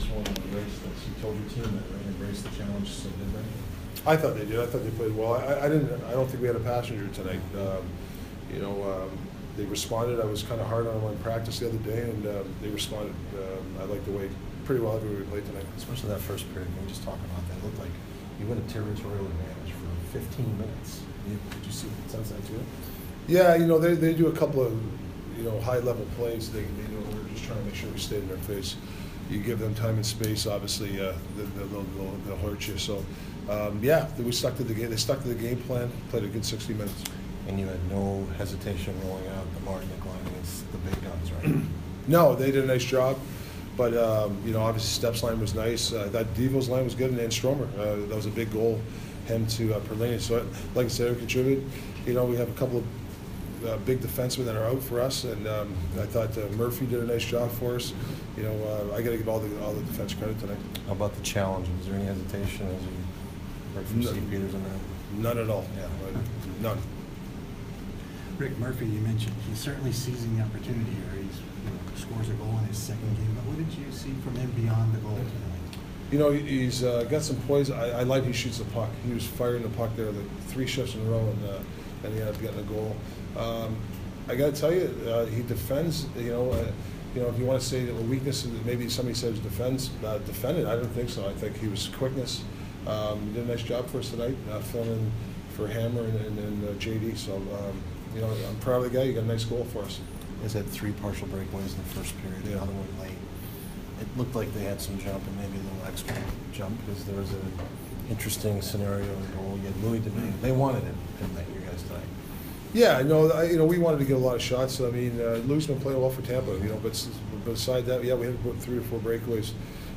Coyotes head coach Rick Tocchet post-game 3/26